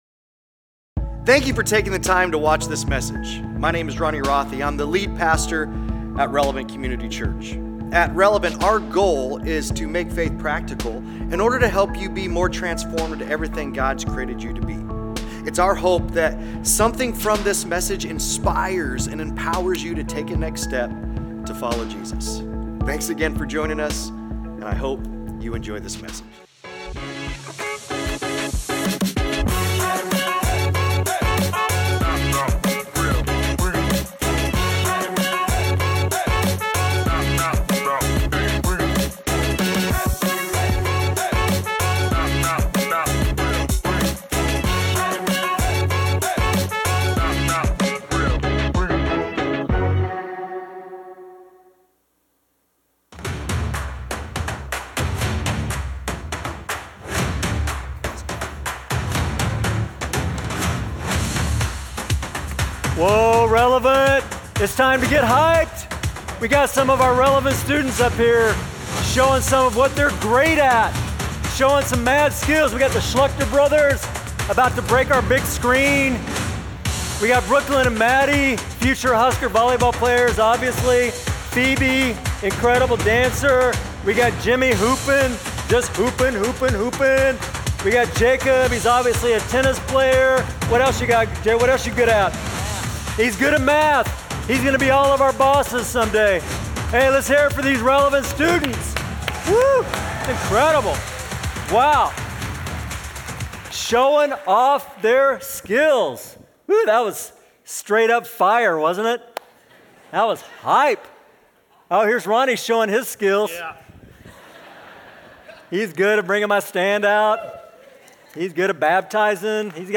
Sunday Sermons Purpose in the Pivotal, Part 2: "Show Us Your Glory!"